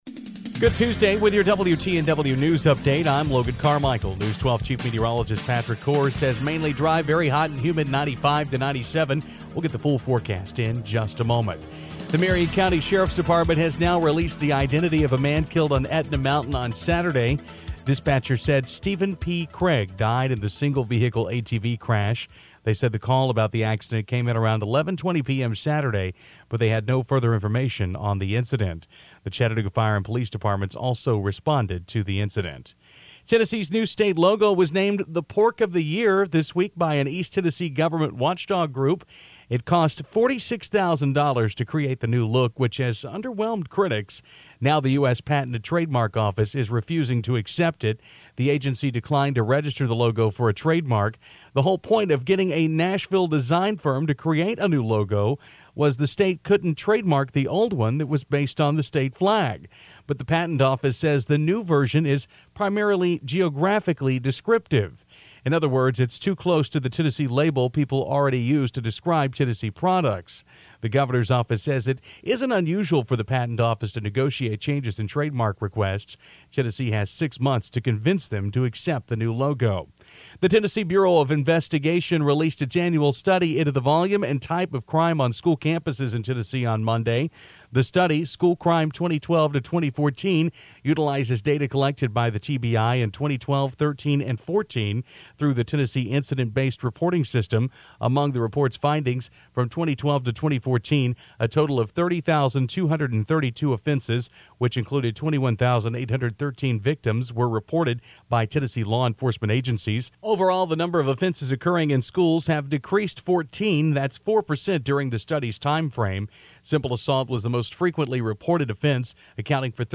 Local news for Tuesday, 6/23/15